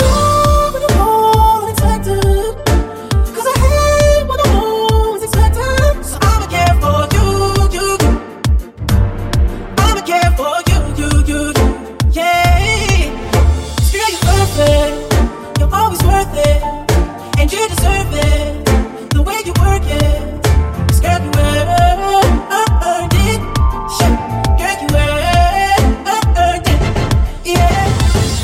Genere: pop,ballads,downtempo,slow,hit